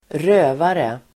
Ladda ner uttalet
Uttal: [²r'ö:vare]